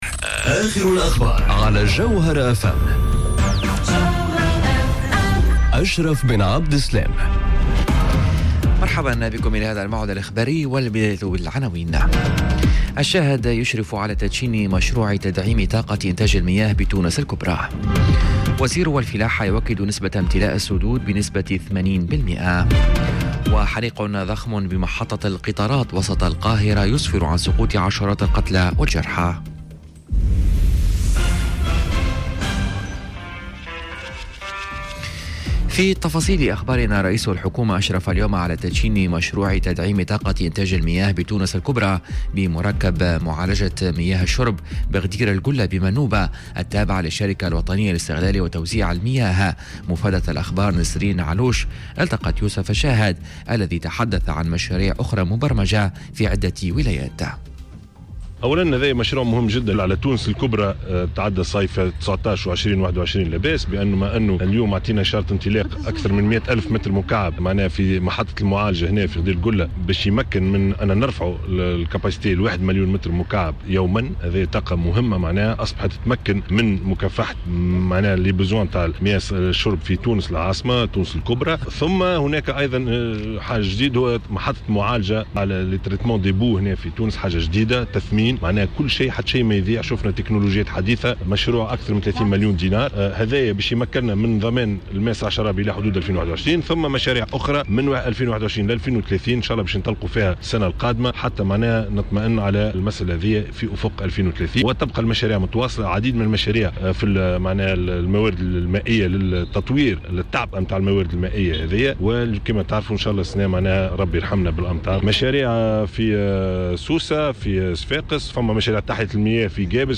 نشرة أخبار منتصف النهار ليوم الإربعاء 27 فيفري 2019